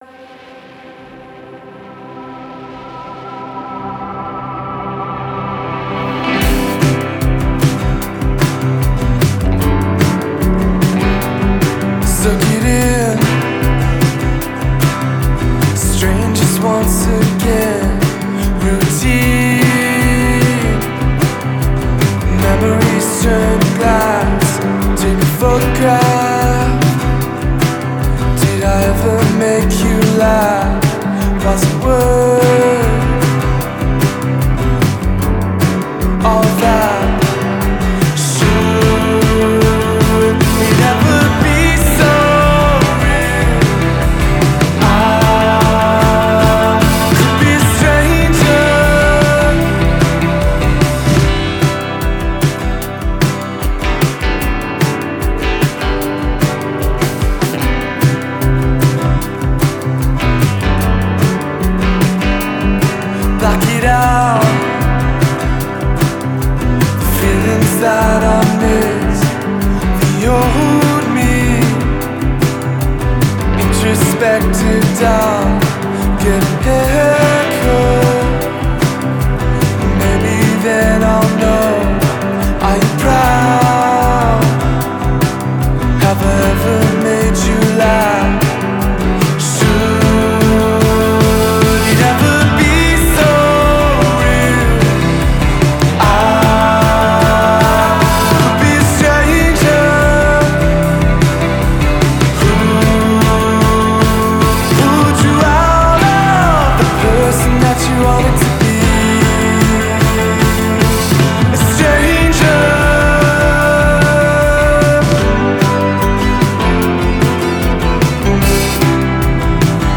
catchy guitar melodies